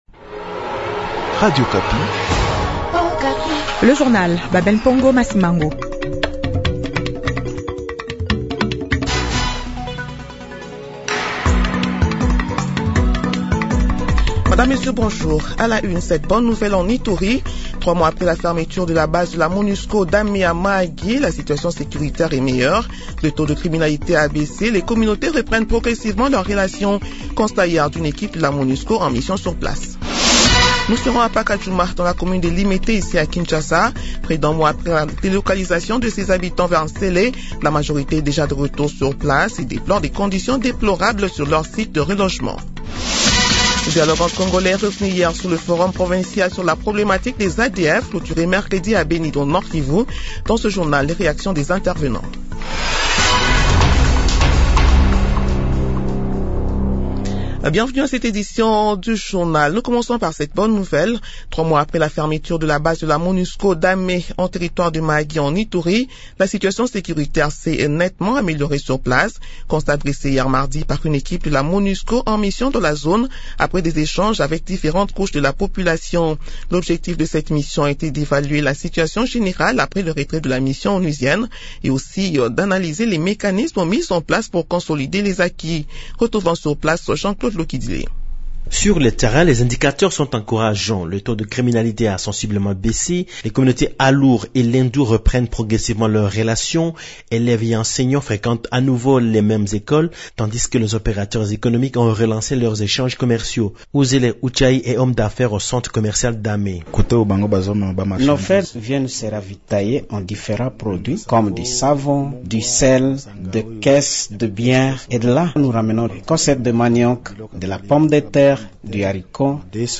Journal matin 8 heures